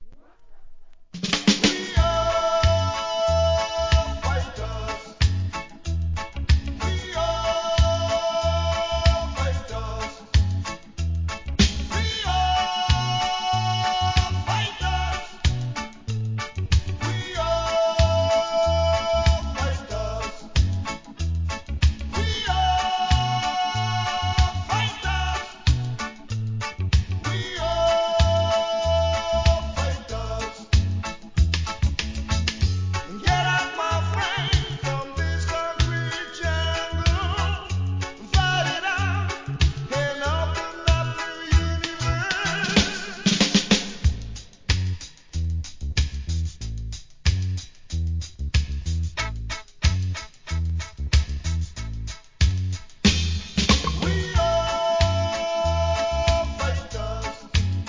¥ 2,750 税込 関連カテゴリ REGGAE 店舗 ただいま品切れ中です お気に入りに追加 1984 JPN